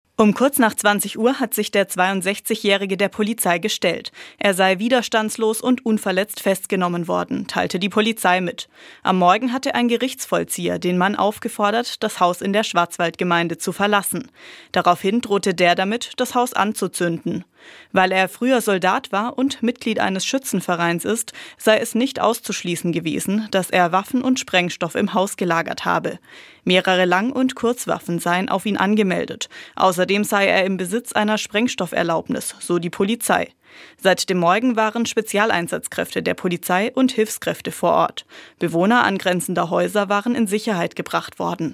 Nachrichten Polizeieinsatz in Unterkirnach beendet